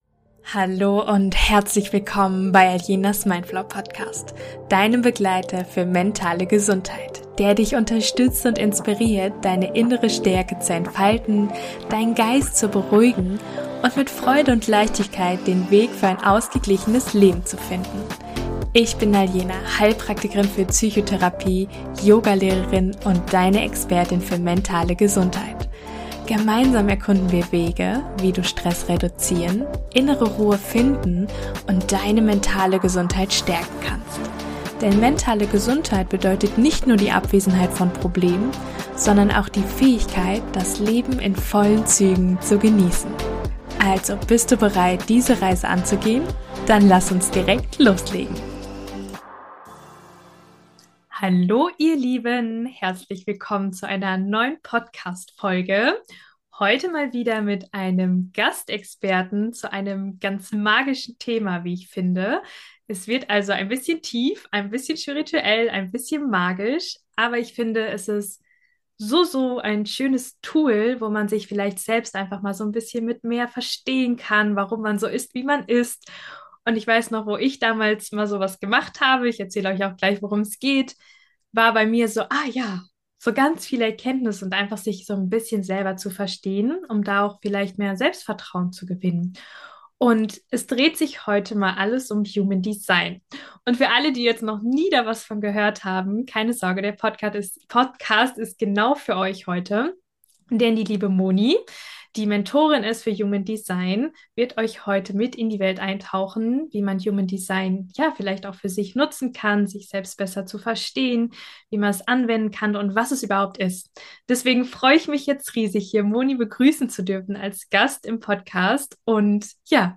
Lerne dich selbst besser zu verstehen mit Human Design - Interview